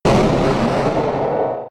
Cri de Méga-Tortank K.O. dans Pokémon X et Y.